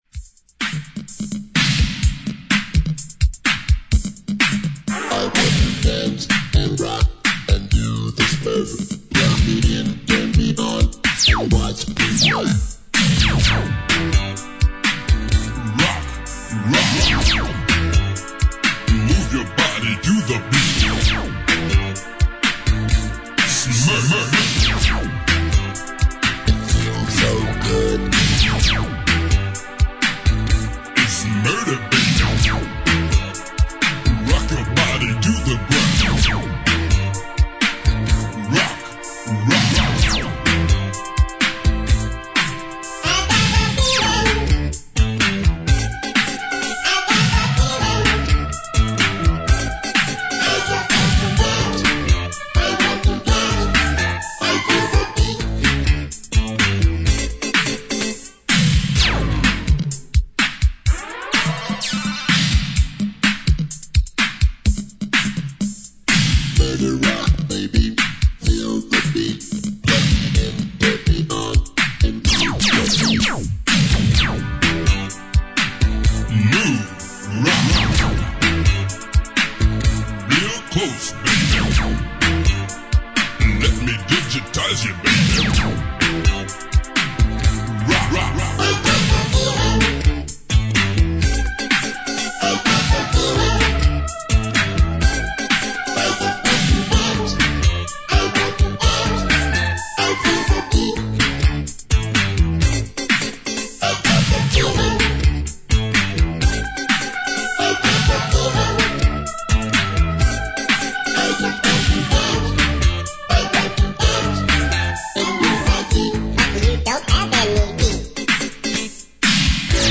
ロボット・ヴォイスが印象的なスペイシーオールドスクール・エレクトロ・ファンク。インスト・ヴァージョンも収録。